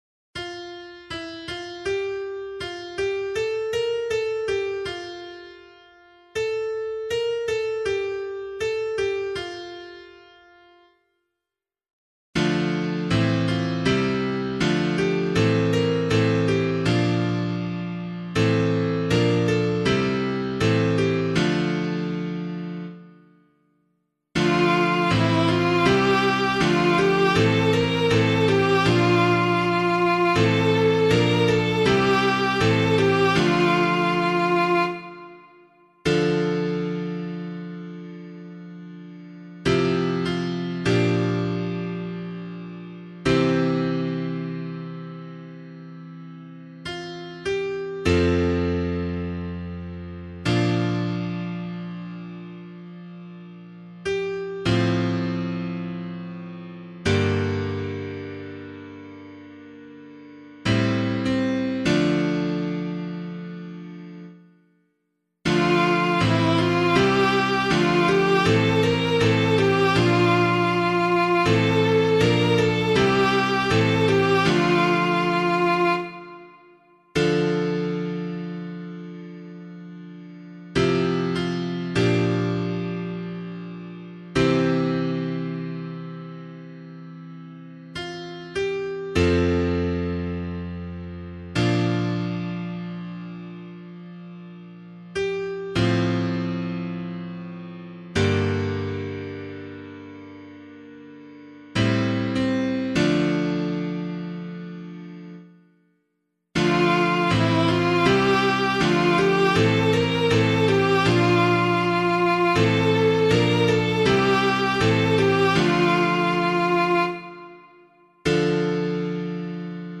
015 Lent 3 Psalm A [APC - LiturgyShare + Meinrad 1] - piano.mp3